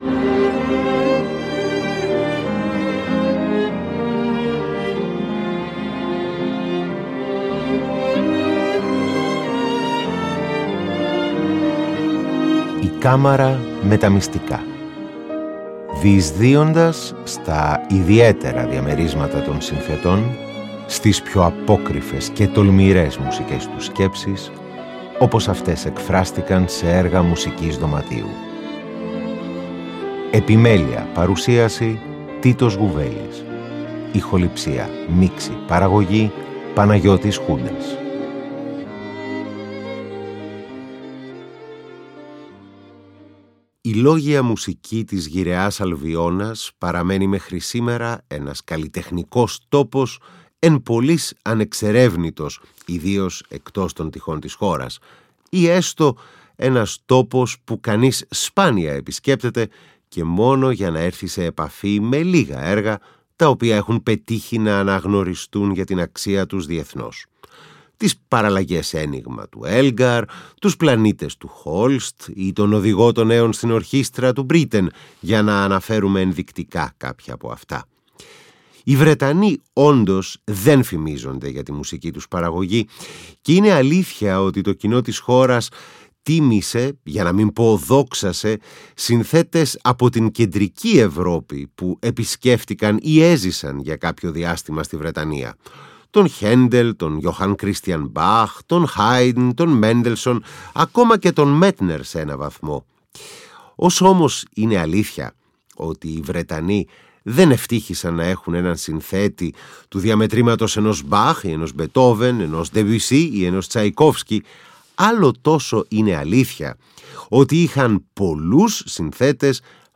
Μία εκπομπή που φιλοδοξεί να διεισδύει στα… ιδιαίτερα διαμερίσματα των συνθετών, στις πιο απόκρυφες και τολμηρές σκέψεις τους, όπως αυτές εκφράστηκαν σε έργα μουσικής δωματίου, παρουσιάζοντας το ευρύτατο ρεπερτόριο της, σε όλο του το φάσμα: αφενός το ιστορικό, δηλαδή από τον 18ο ως και τον 21ο αιώνα, αφετέρου το οργανικό, μέσα από καθιερωμένους αλλά και πιο ασυνήθιστους συνδυασμούς οργάνων.